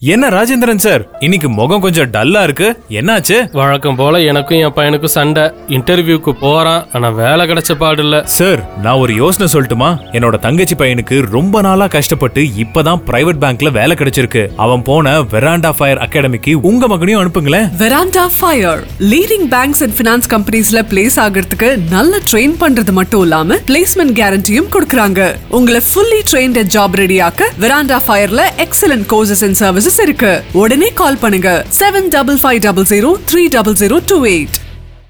Veranda Phire Academy – Radio Commercial